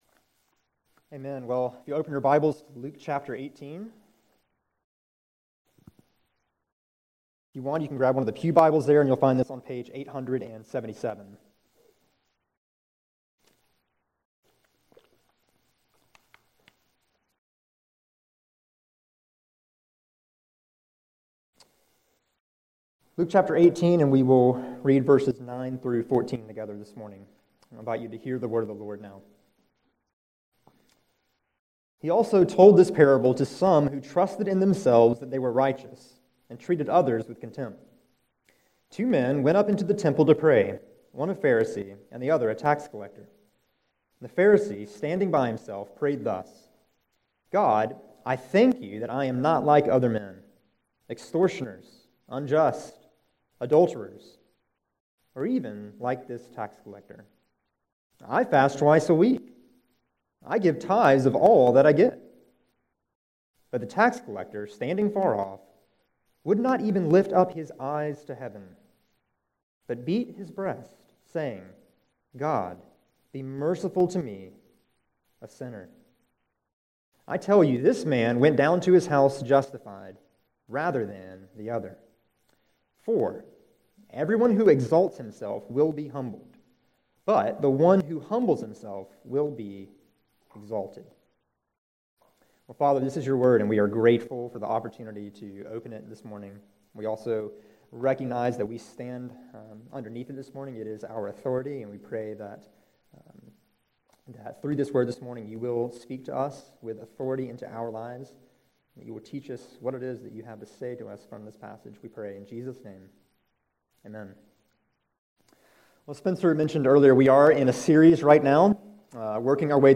July 9, 2017 Morning Worship | Vine Street Baptist Church